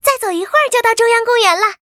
文件 文件历史 文件用途 全域文件用途 Fifi_amb_01.ogg （Ogg Vorbis声音文件，长度1.8秒，114 kbps，文件大小：25 KB） 源地址:游戏语音 文件历史 点击某个日期/时间查看对应时刻的文件。